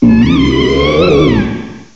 cry_not_gourgeist.aif